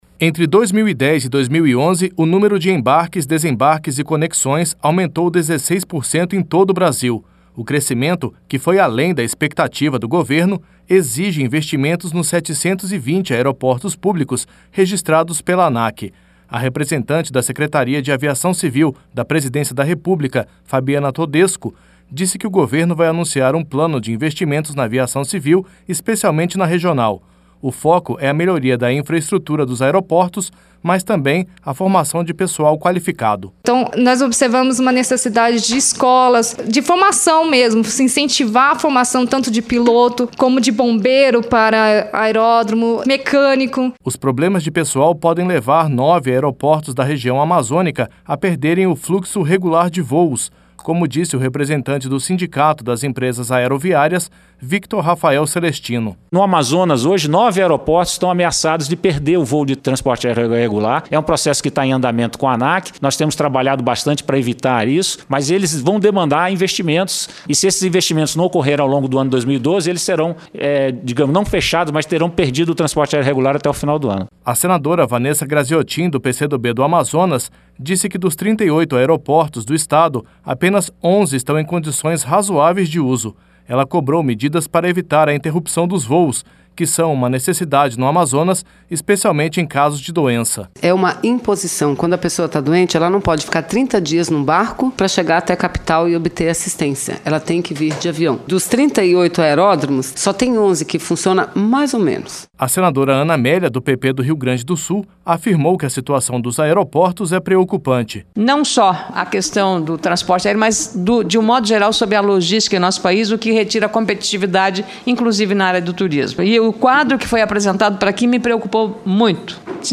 LOC: O BRASIL DEVE TER EM BREVE UMA POLÍTICA DE INCENTIVO PARA A AVIAÇÃO REGIONAL. LOC: O ASSUNTO FOI DEBATIDO NA TARDE DESTA TERÇA-FEIRA EM AUDIÊNCIA PÚBLICA DA COMISSÃO DE DESENVOLVIMENTO REGIONAL E TURISMO.